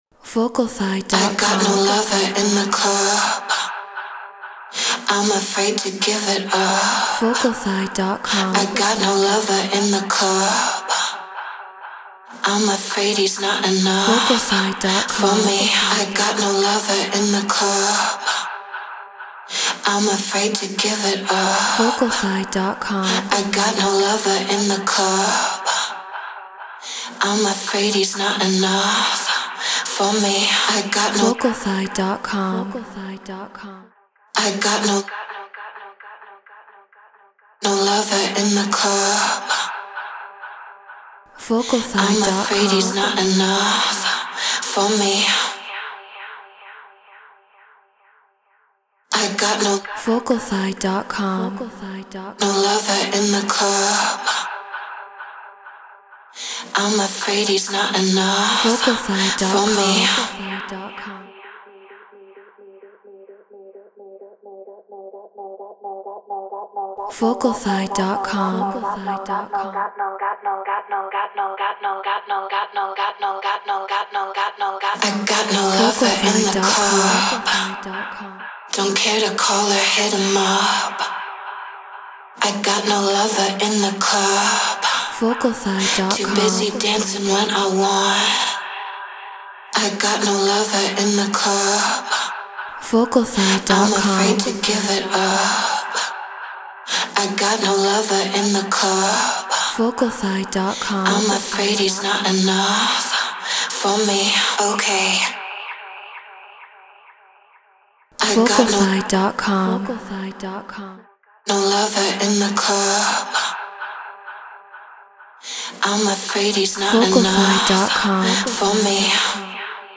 Tech House 130 BPM G#min